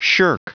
Prononciation du mot shirk en anglais (fichier audio)
Prononciation du mot : shirk